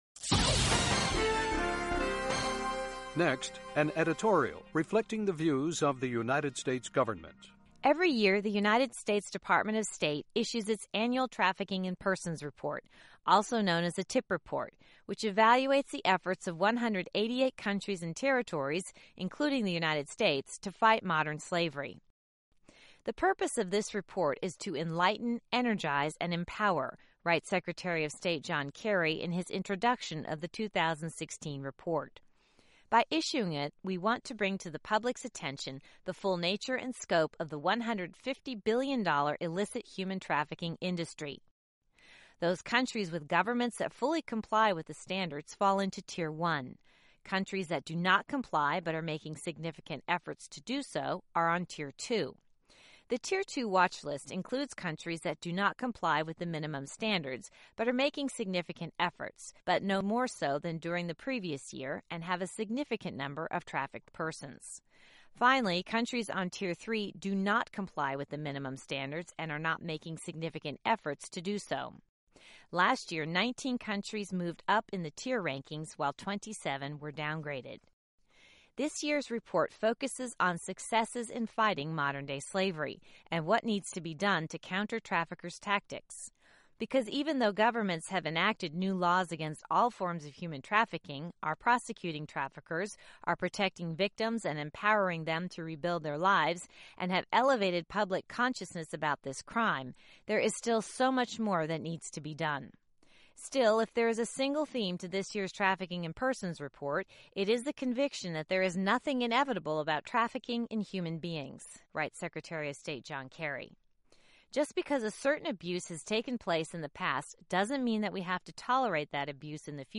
Reflecting the Views of the U.S. Government as Broadcast on The Voice of America